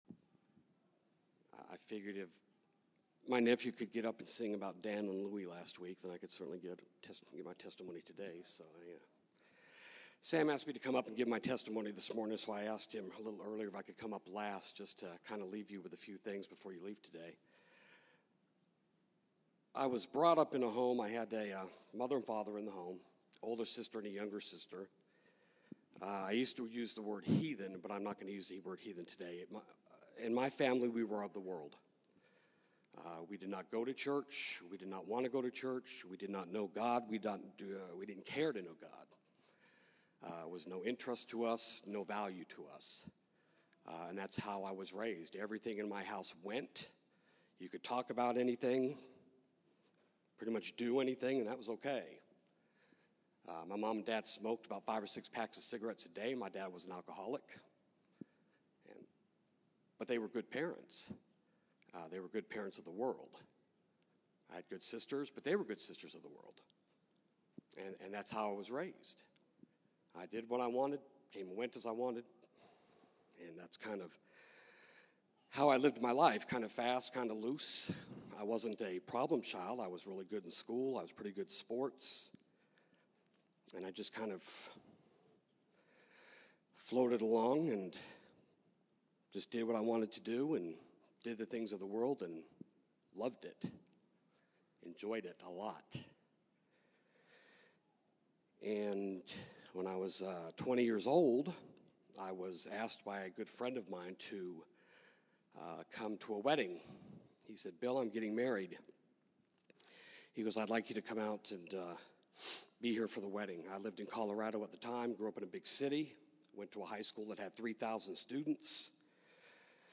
2014 Category: Testimonies